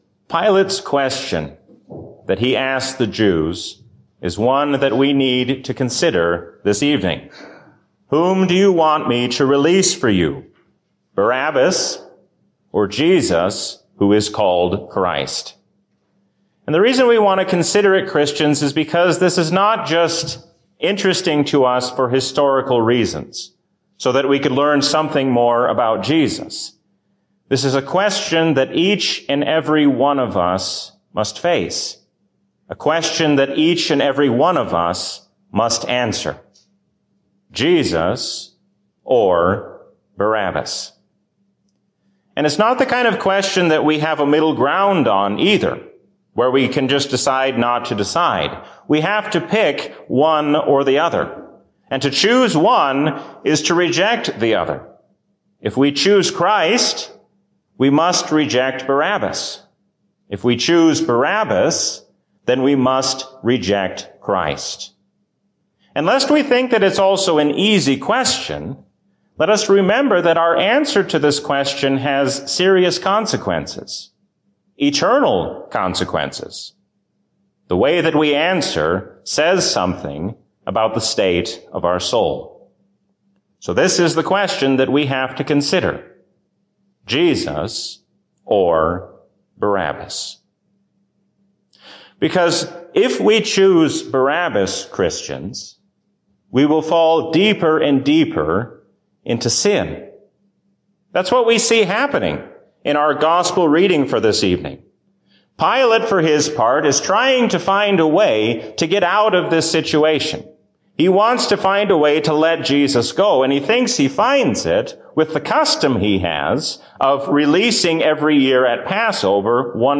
A sermon from the season "Lent 2021." To choose Barabbas is to fall into sin, but Christ brings us forgiveness of sin.